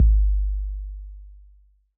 808 KICK.wav